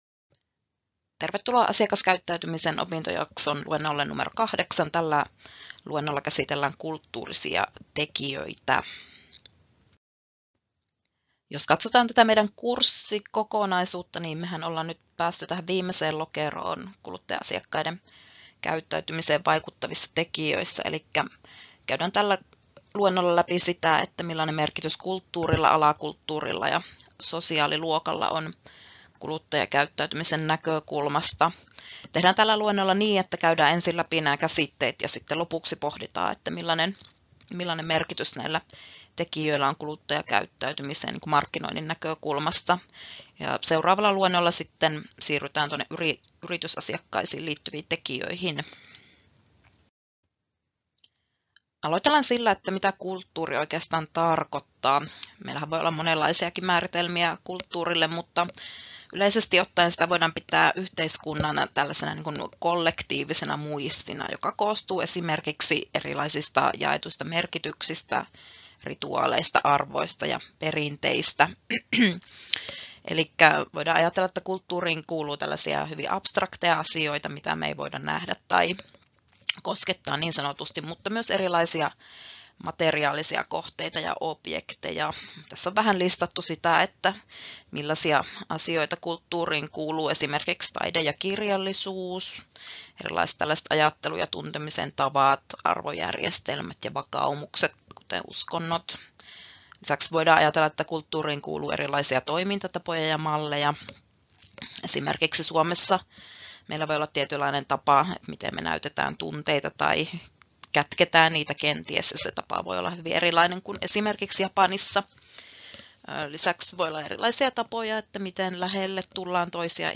Luento 8: Kulttuuriset tekijät — Moniviestin